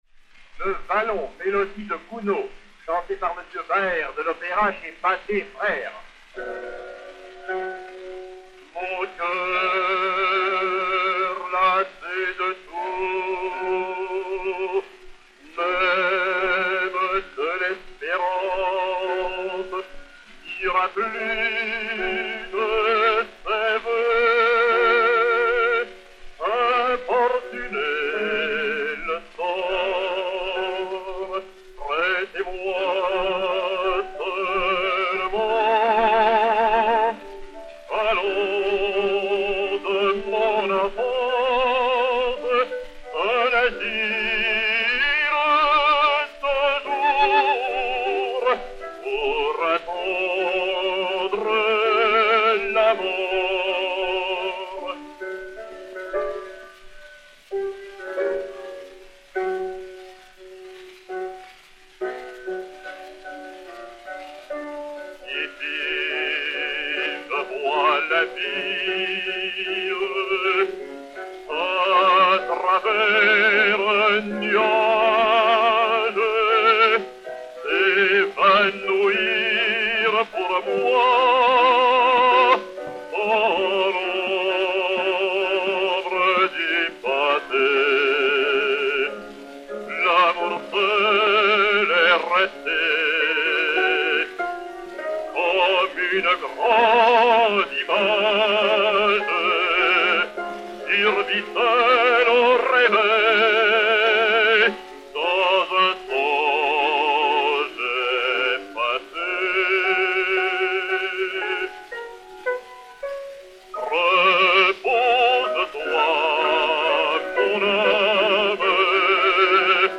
Piano
Pathé saphir 90 tours n° 478, enr. en 1904